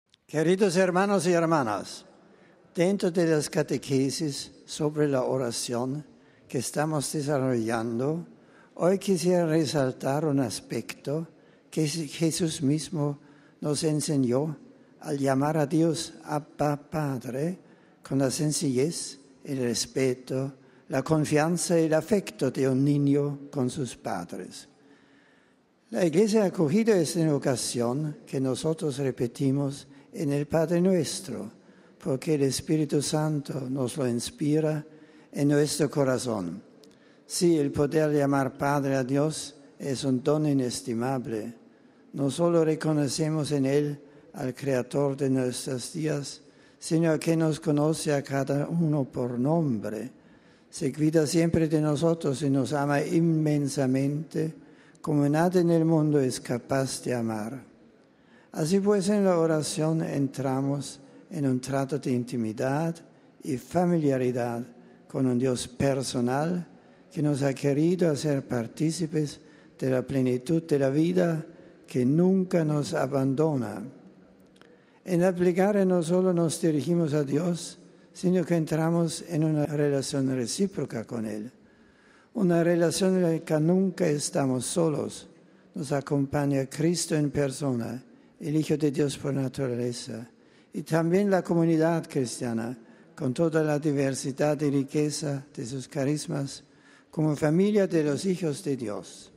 (RV).- Como todos los miércoles, también esta mañana, a las 10,30 el Santo Padre Benedicto XVI celebró su tradicional audiencia general, que tuvo lugar en la Plaza de San Pedro y en la que participaron varios miles de fieles y peregrinos de numerosos países.
En el resumen de su catequesis en nuestro idioma Benedicto XVI dijo: (Audio) RealAudio